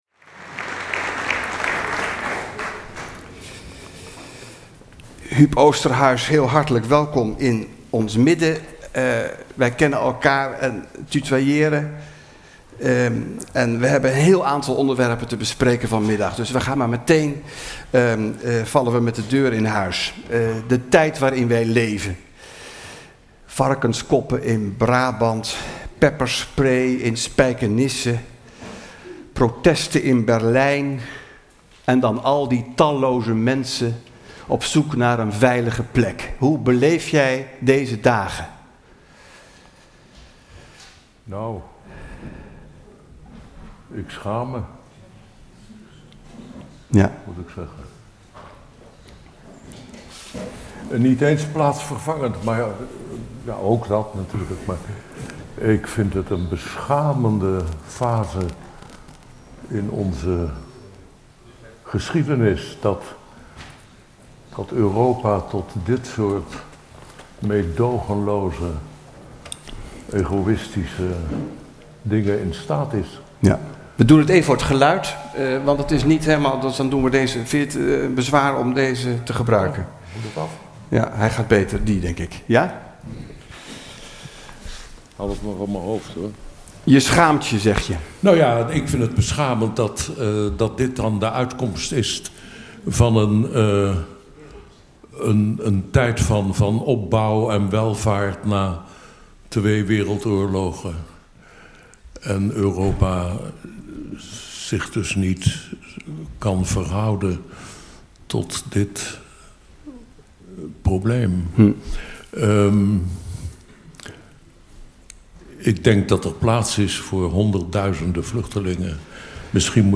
Een gesprek in de serie Areopagus Bloemendaal met als gast Huub Oosterhuis. Thema: Hoe beleef jij de tijd waarin wij leven?
Deze aflevering is opgenomen op 24 januari 2016 in de Dorpskerk van Bloemendaal.